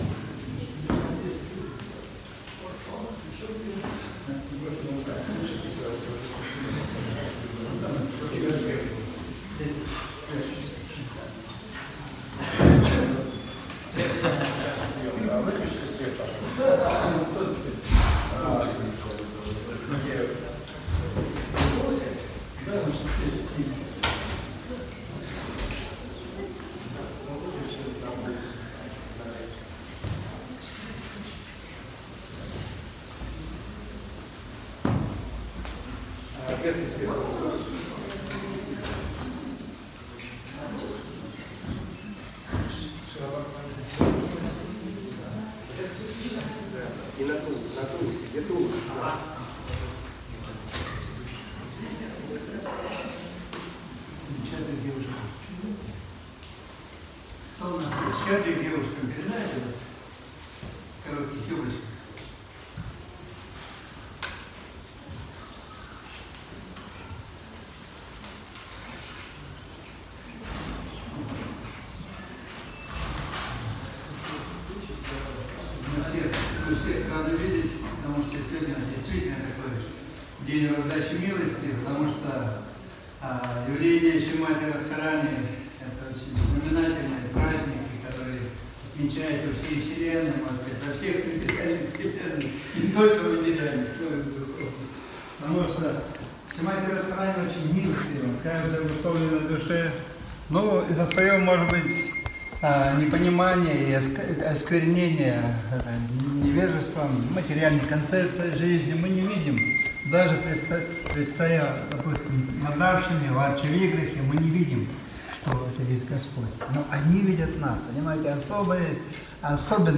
Лахта, Санкт-Петербург
Лекции полностью